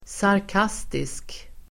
Ladda ner uttalet
Uttal: [sark'as:tisk]